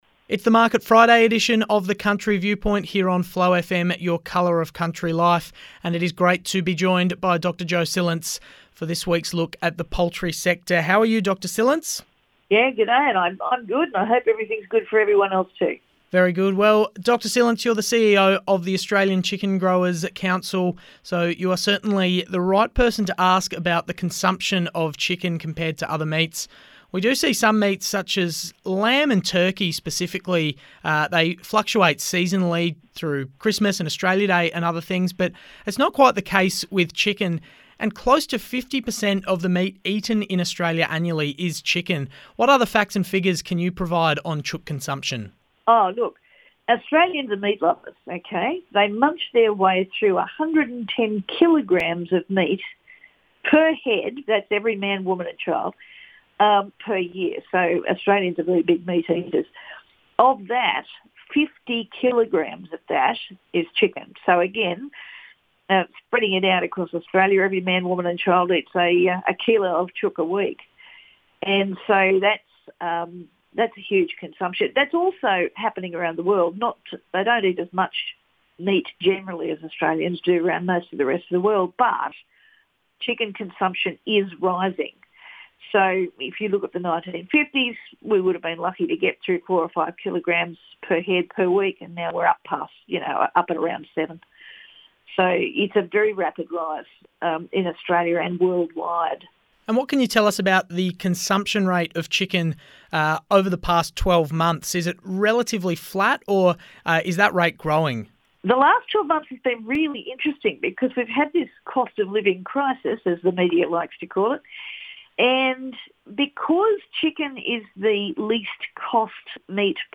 Radio Interviews on Flow FM, South Australia